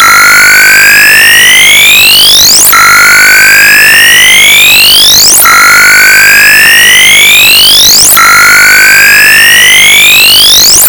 30853 LD HL,768 Make the safe-key-getting celebratory sound effect